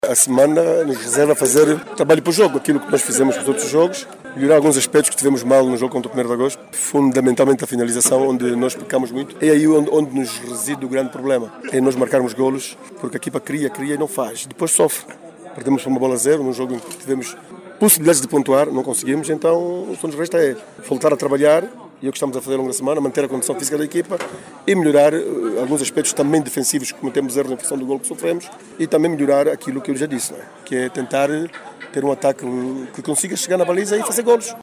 Maria do Rosário Bragança, disse ainda que existe uma boa articulação entre o Governo Angolano e a Nunciatura Apostólica para o sucesso das três missas campais que Papa Leão XIV, vai celebrar no Kilamba, na Muxima e em Saurimo.